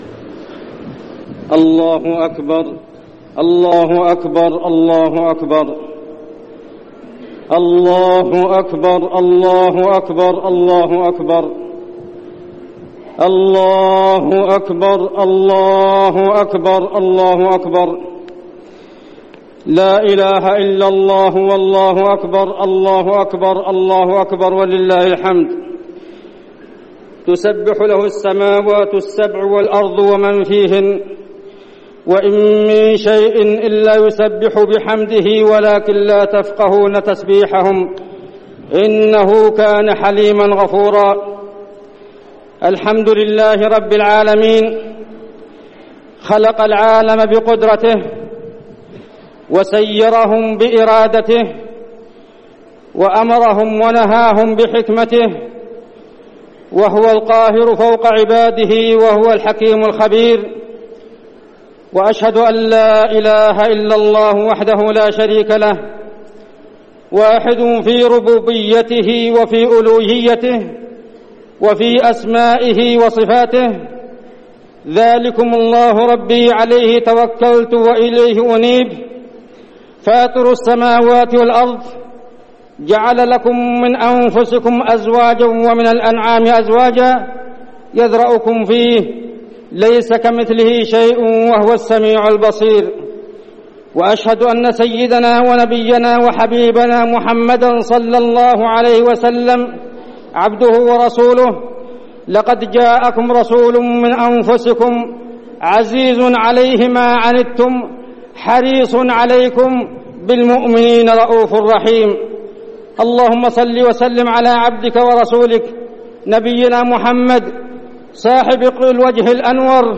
خطبة عيد الفطر - المدينة - الشيخ عبدالله الزاحم
تاريخ النشر ١ شوال ١٤١٢ هـ المكان: المسجد النبوي الشيخ: عبدالله بن محمد الزاحم عبدالله بن محمد الزاحم خطبة عيد الفطر - المدينة - الشيخ عبدالله الزاحم